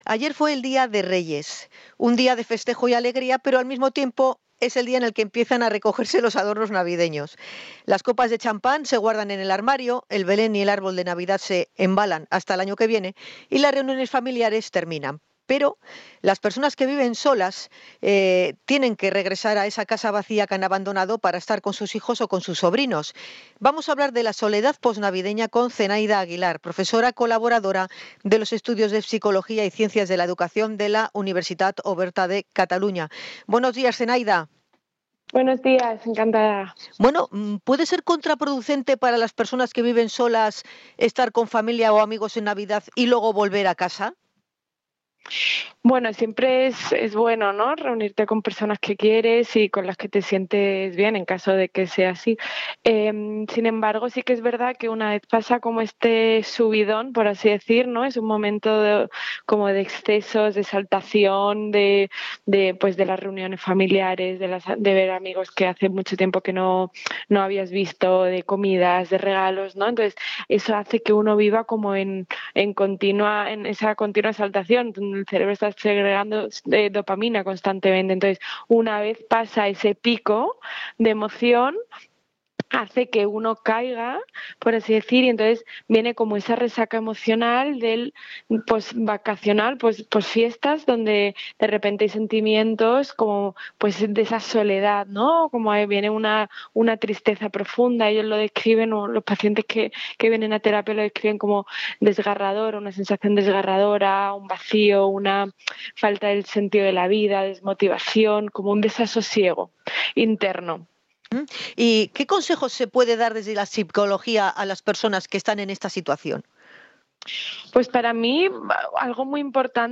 INT.-SOLEDAD-POSNAVIDENA.mp3